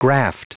Prononciation du mot graft en anglais (fichier audio)
Prononciation du mot : graft